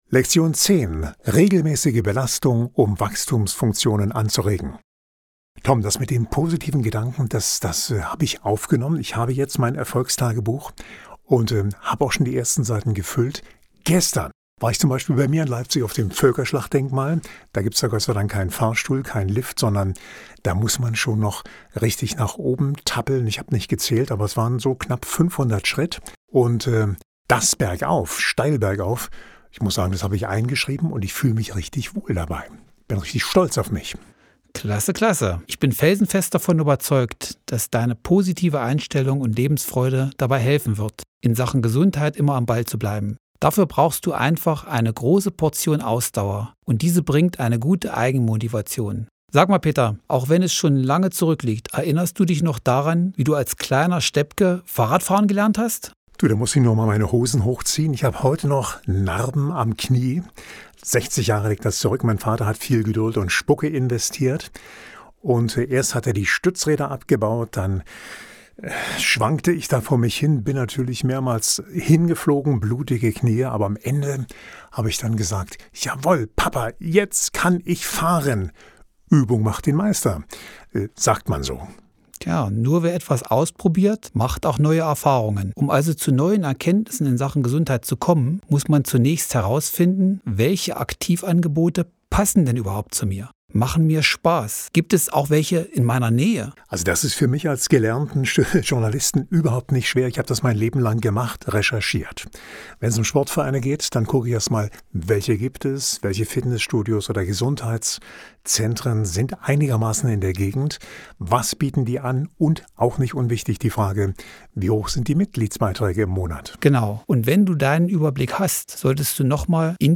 Wir präsentieren Ihnen hier über zwölf Wochen den Dialog